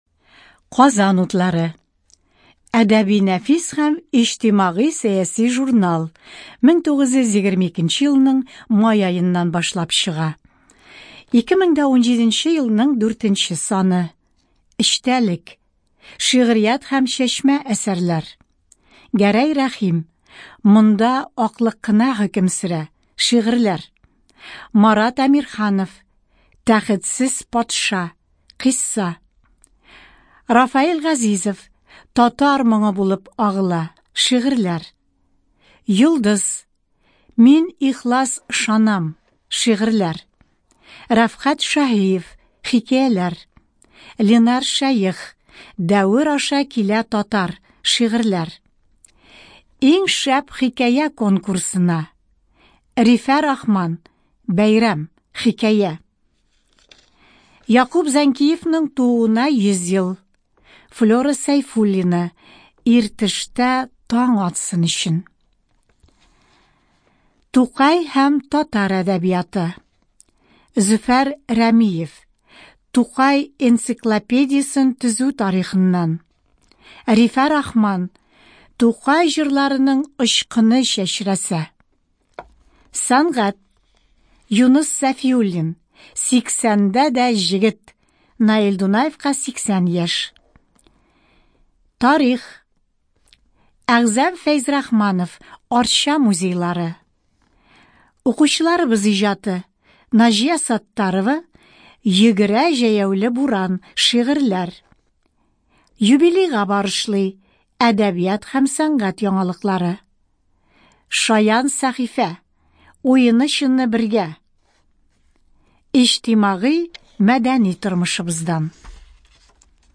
Студия звукозаписиТатарская республиканская специальная библиотека для слепых и слабовидящих